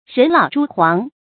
人老珠黃 注音： ㄖㄣˊ ㄌㄠˇ ㄓㄨ ㄏㄨㄤˊ 讀音讀法： 意思解釋： 舊時比喻女子老了被輕視，就象因年代久遠而失去光澤的珍珠一樣不值錢。